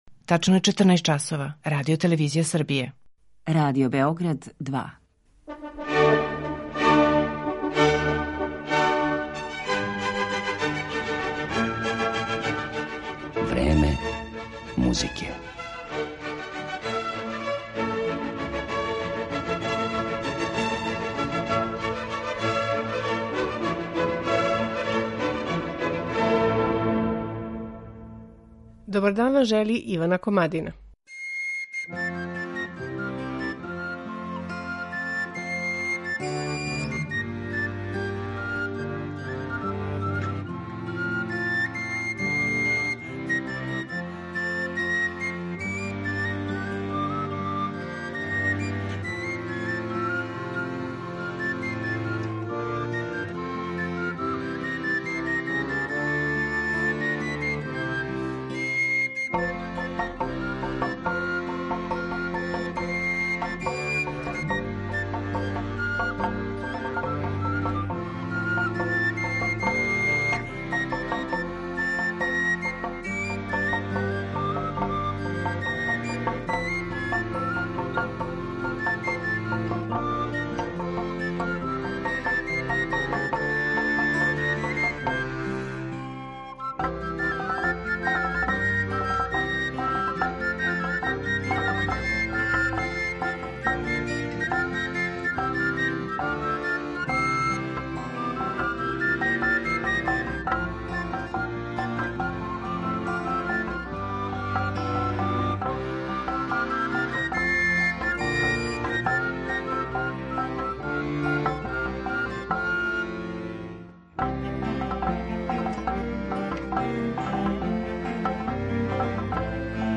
Чалапарта је најпрепознатљивији баскијски инструмент: масивни дрвени сто са неколико дасака положених уздужно, по којима се свира великим дрвеним батовима или чуњевима.